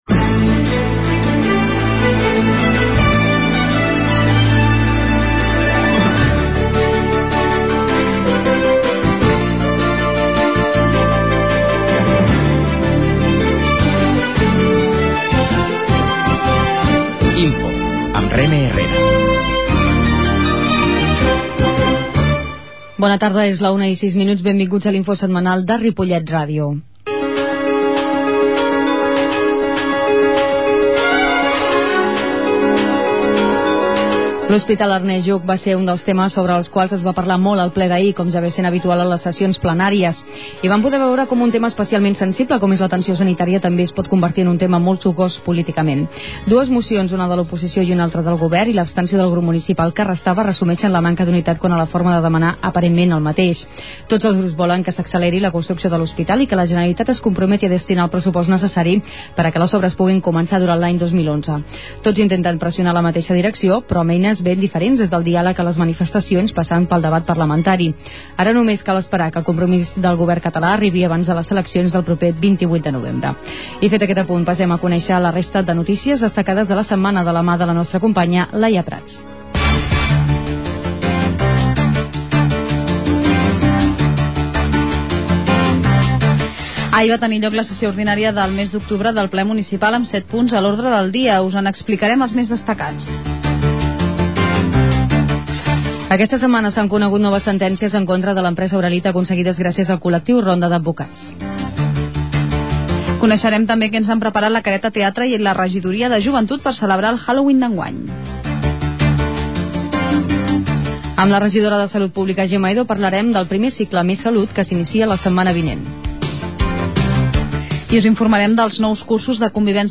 A l'edició de l'informatiu setmanal del 29 d'octubre hem fet un resum dels acords presos al Ple Municipal ordinari del mes d'octubre, celebrat el passat dia 29. També ens ha acompanyat la regidora de Salut Pública de l'Ajuntament de Ripollet, Gemma Edo, per comentar el 1er Cicle Més Salut que es durà a terme al municipi durant el mes de novembre.
La qualitat de so ha estat reduïda per tal d'agilitzar la seva baixada.